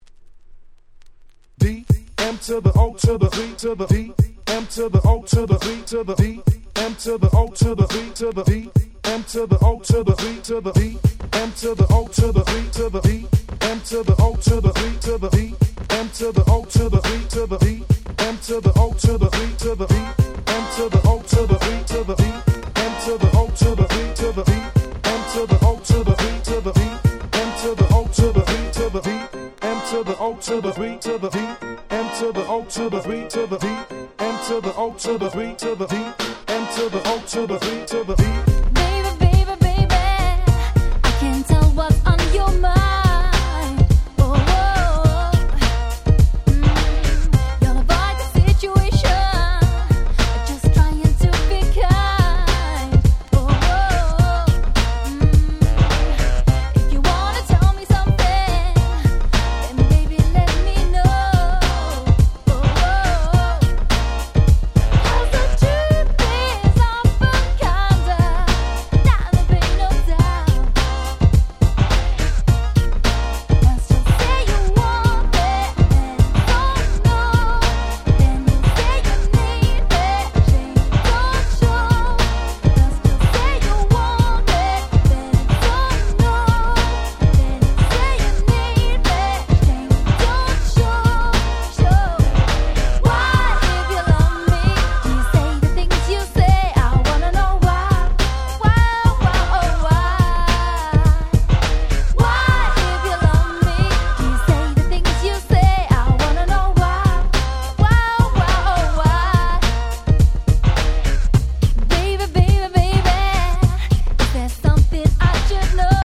94' Very Nice R&B !!
90's キャッチー系